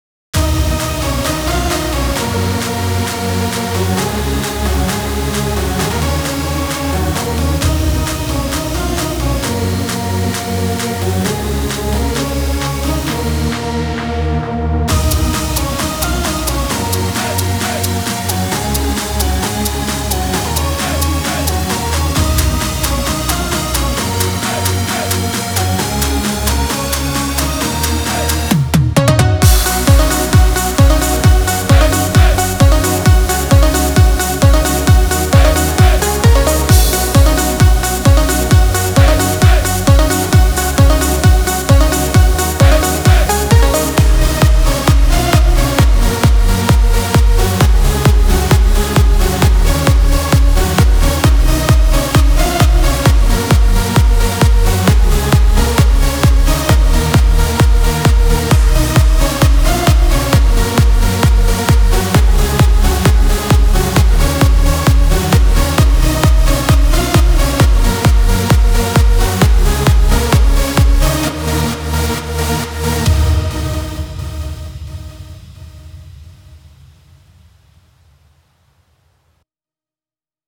טראנסים הזוייים.WAV היתה בעייה בהעלאה מקוה שעכשיו זה בסדר…